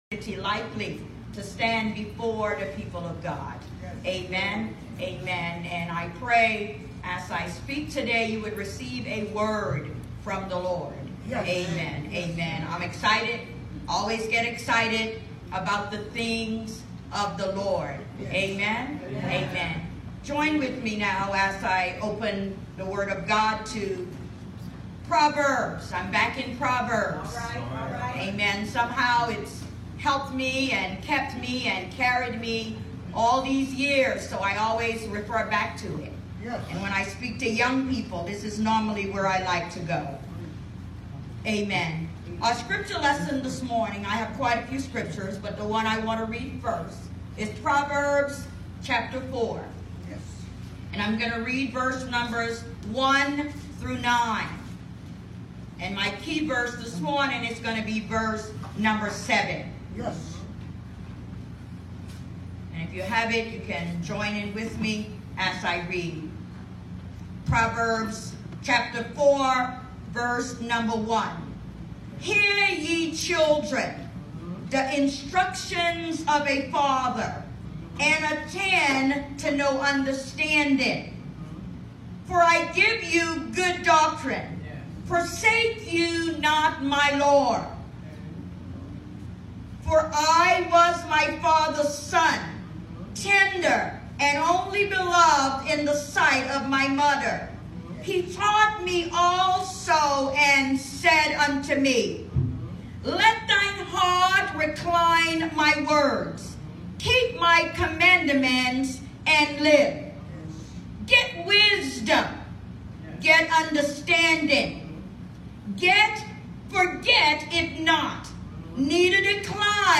Sunday 11:00am New Jerusalem MB Church